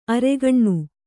♪ aregaṇṇu